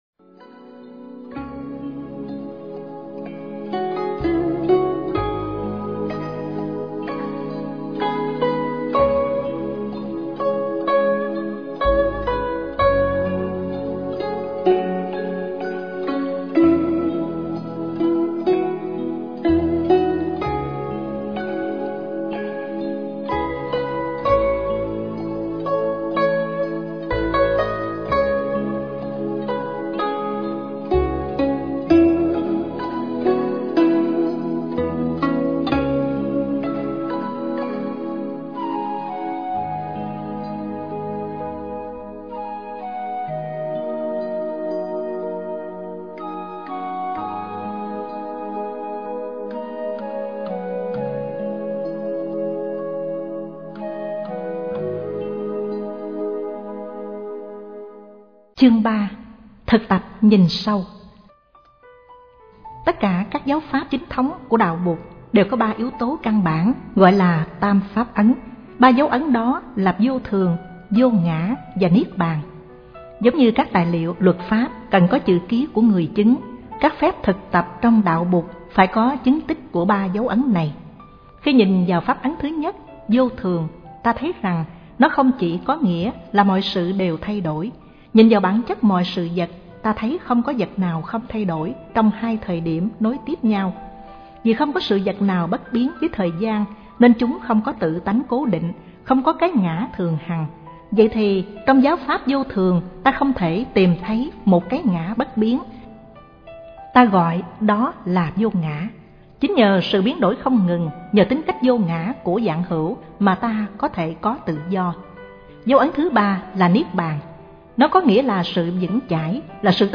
Sách nói Không Diệt Không Sinh Đừng Sợ Hãi - Thích Nhất Hạnh - Sách Nói Online Hay